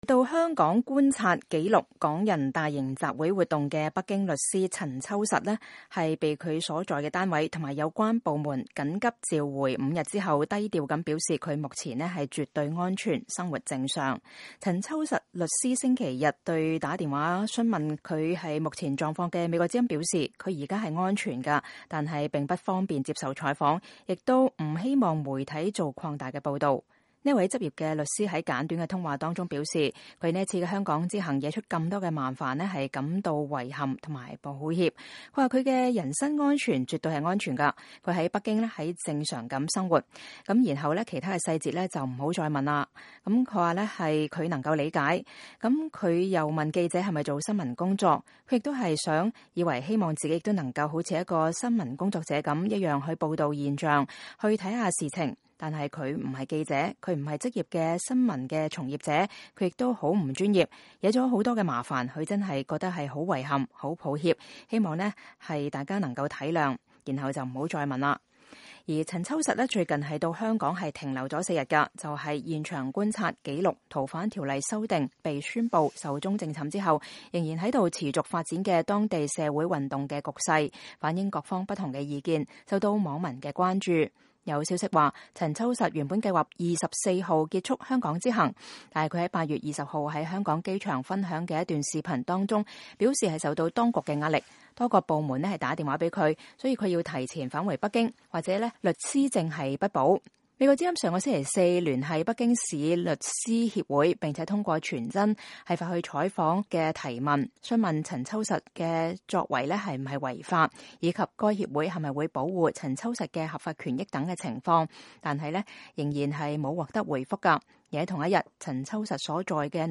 這位執業律師在簡短通話中表示，他此次香港之行惹出這麼多麻煩，感到遺憾、抱歉。